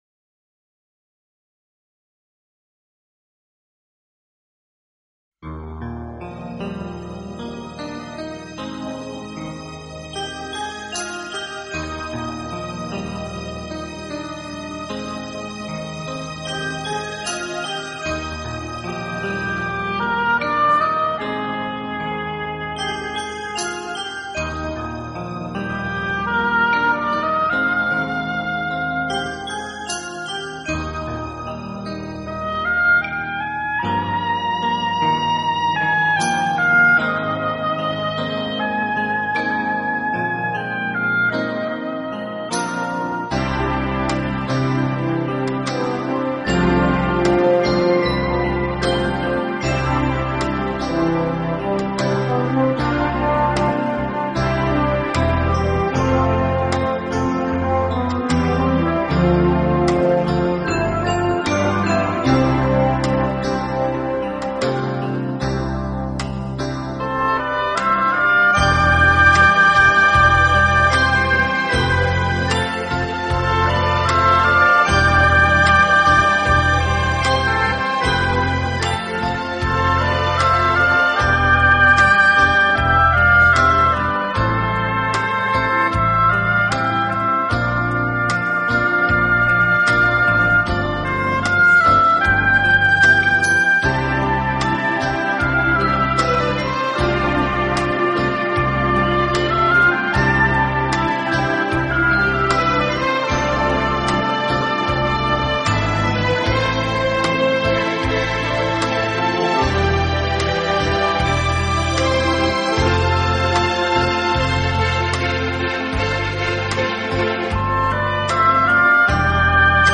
同时其录音又极其细致、干净、层次分明，配器简洁明了，是